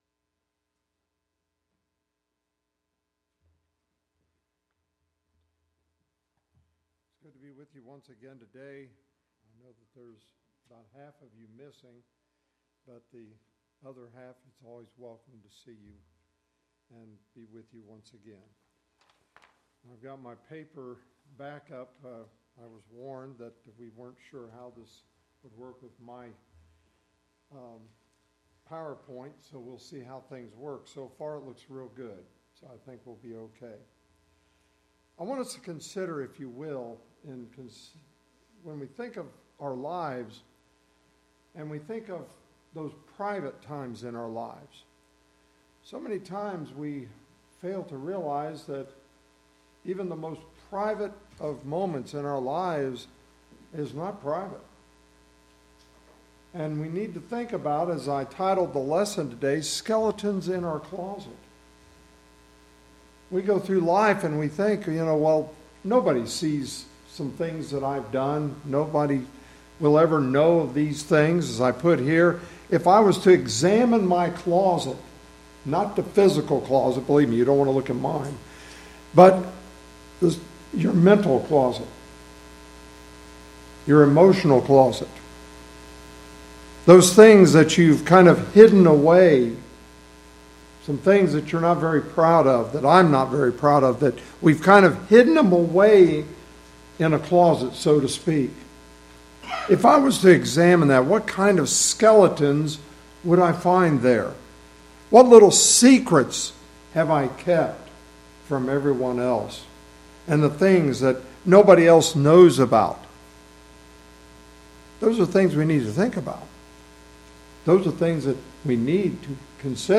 The sermon teaches that humans often attempt to hide sin, but Scripture repeatedly shows that nothing is hidden from God.